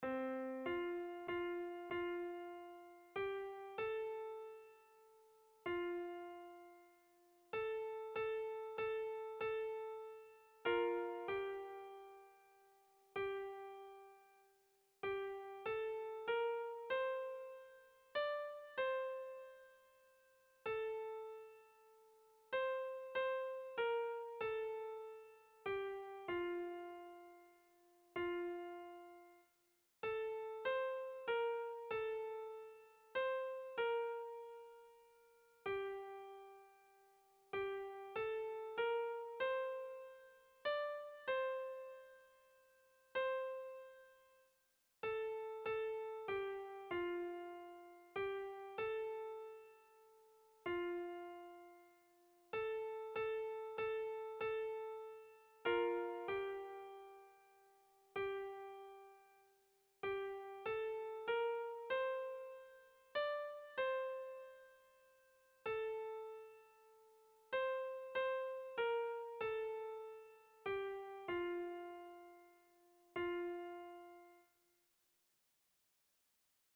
Sentimenduzkoa
Hamarreko txikia (hg) / Bost puntuko txikia (ip)
ABDEB